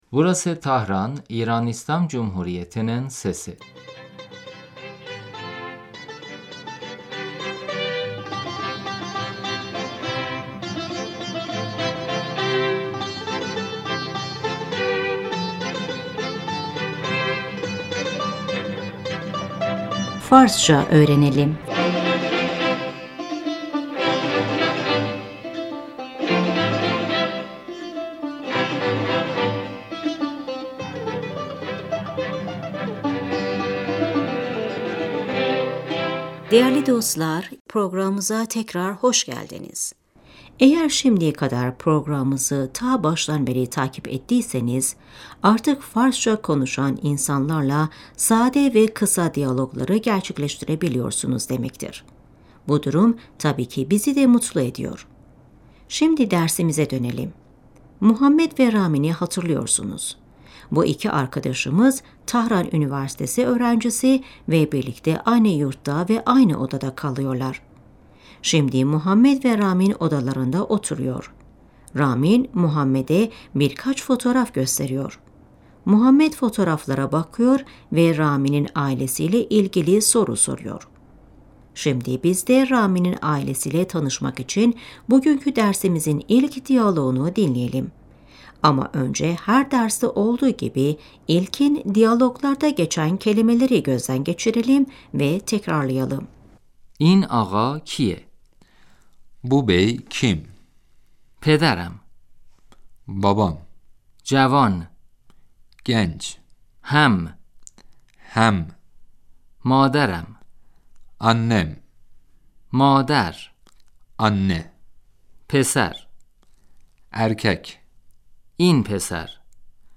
صدای ورق زدن کاغذ - صدای استکان و خوردن چای Kağıtları karıştırma sesi, çay bardağı sesi ve çay içme sesi محمد - این آقا کیه ؟